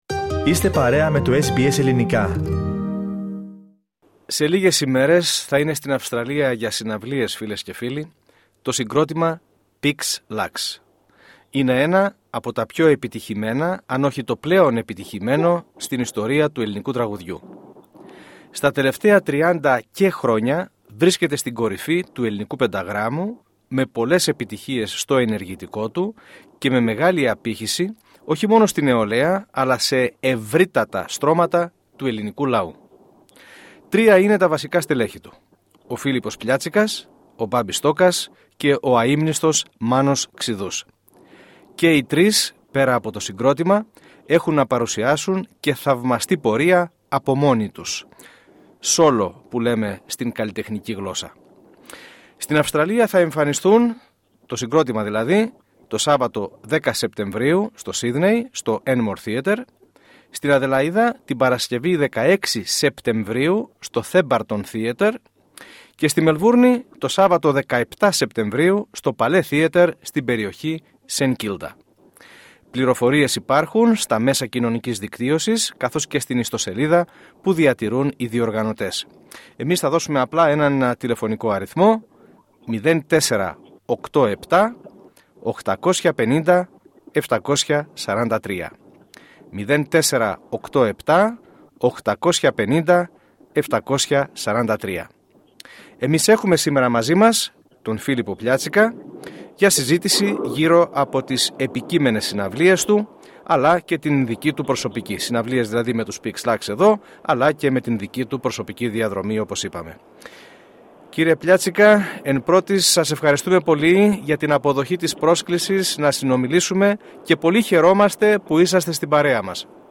Λίγες ημέρες πριν τον ερχομό τους στην Αυστραλία μίλησε στο πρόγραμμά μας, SBS Greek ο Φίλιππος Πλιάτσικας.